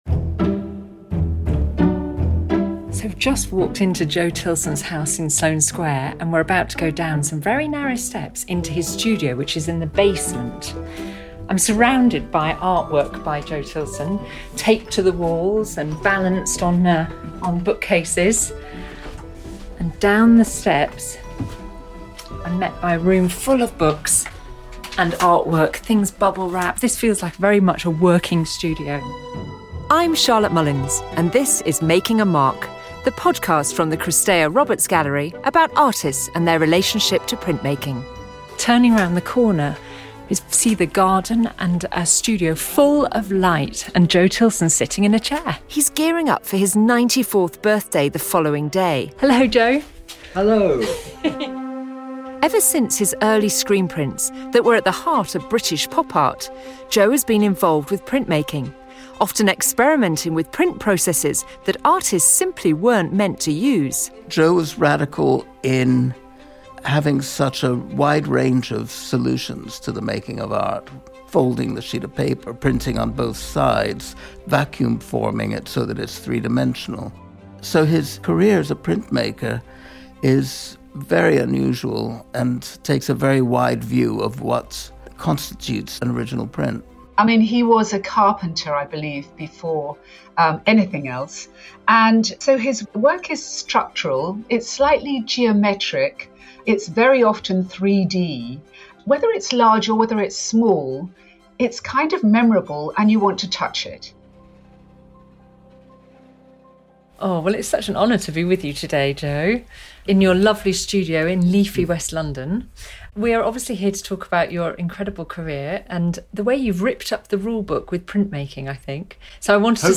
As a lifelong dedicated and subversive printmaker, we meet Tilson in his London studio on the eve of his 94th birthday, to discuss the preoccupations, inspirations, philosophy, and methods, that have been the focus of his graphic works for over half a century.